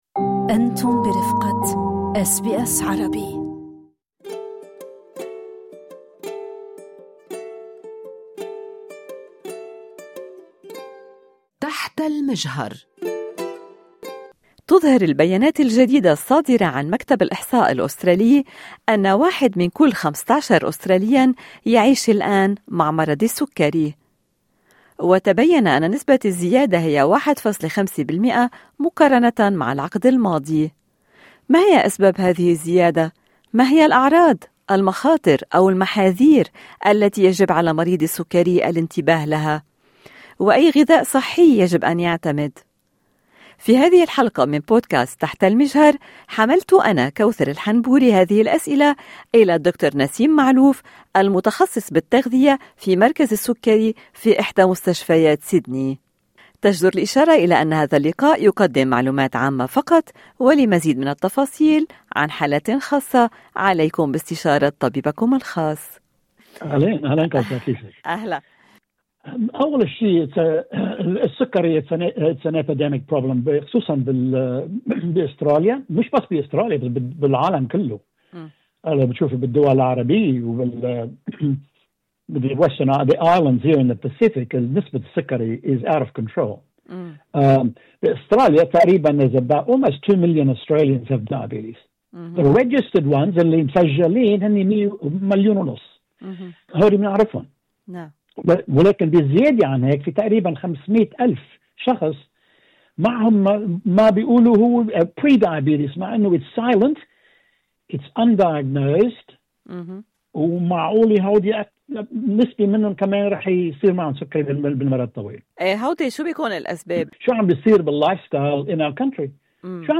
اشارة الى أن هذا اللقاء يقدم معلومات عامة فقط لمزيد من التفاصيل عن حالات خاصة عليكم باستشارة طبيبكم الخاص بدأ بالقول أن السكري هو مشكلة وبائية منتشرة في أستراليا والعالم ونسبة الزيادة فيها صارت خارجة عن السيطرة.